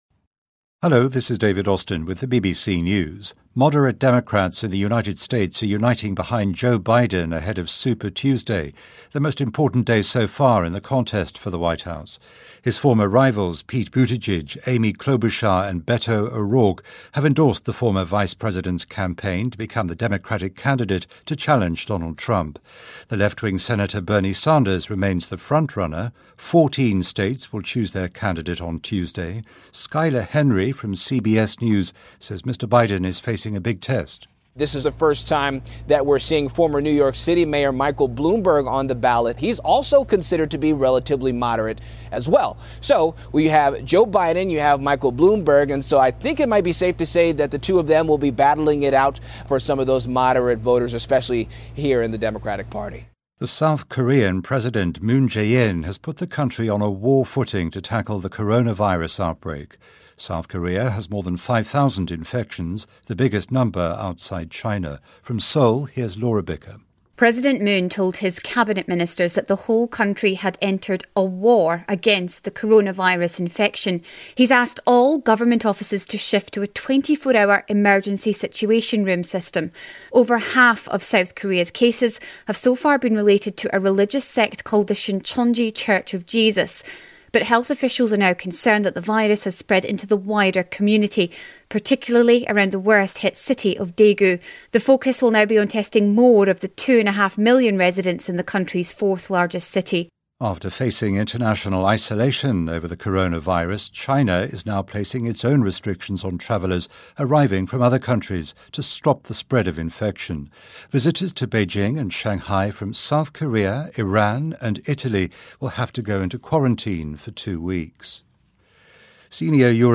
News
英音听力讲解:韩国进入备战状态应对新冠病毒